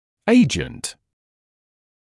[‘eɪʤənt][‘эйджэнт]агент; фактор; средство